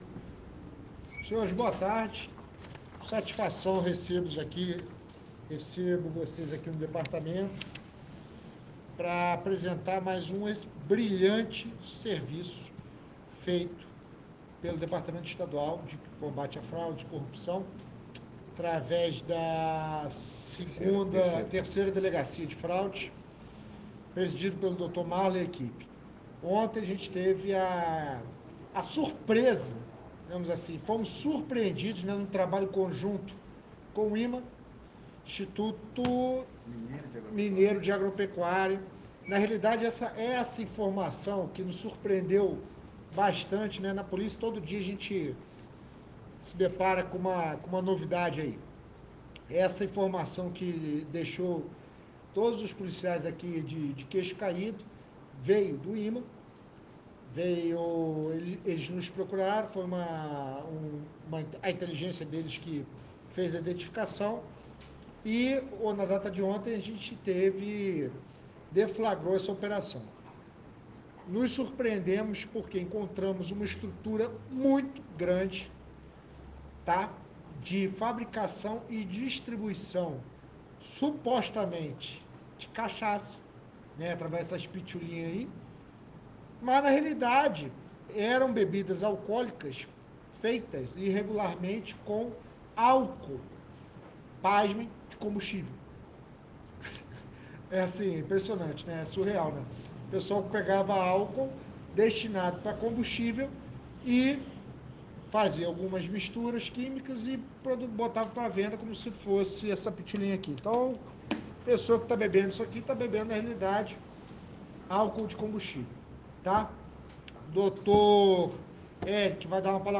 Coletiva.mp3